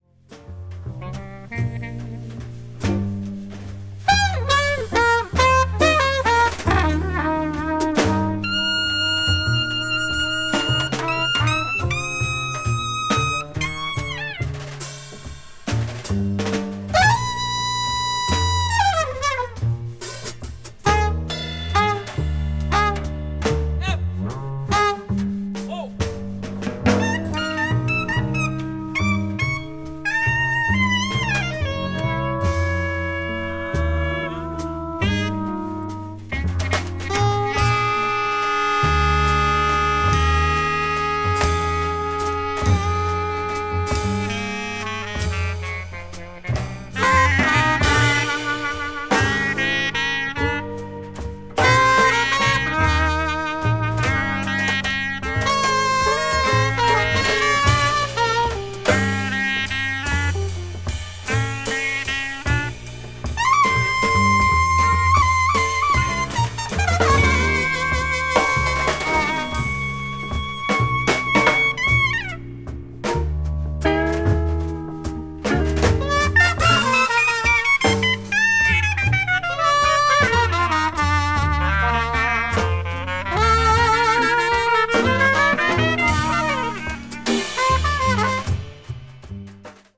シリーズ他作品と同様にエネルギーと先鋭性に満ちた素晴らしい演奏。